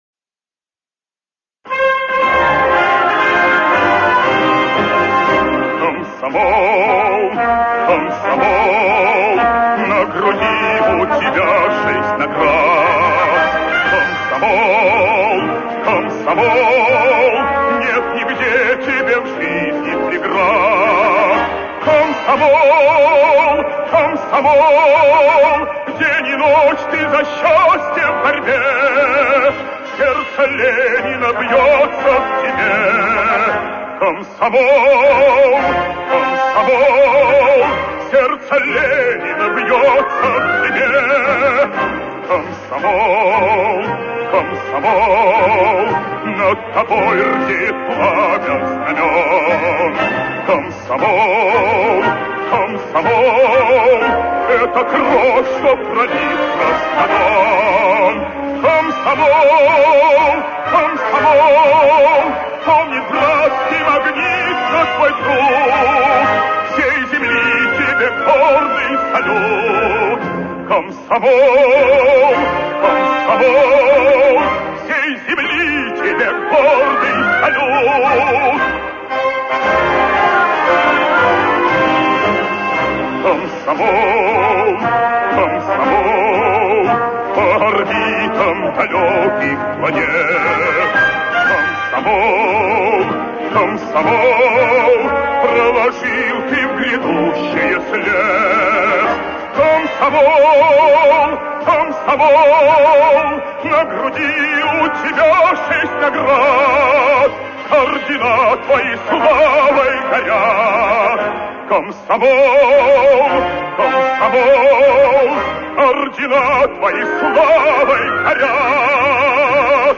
Официозная "брежневская" песня о комсомоле